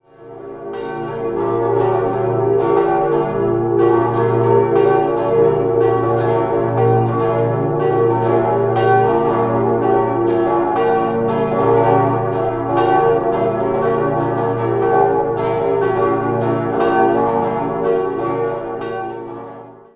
5-stimmiges erweitertes Salve-Regina-Geläute: b°-d'-f'-g'-b' Die vier kleinen Glocken besitzen Scheibenklöppel und wurden 1946 gegossen, die große Dreifaltigkeitsglocke erklingt mit einem Rundballenklöppel, wiegt ca. 3.400 kg und wurde im Jahr 1951 gegossen.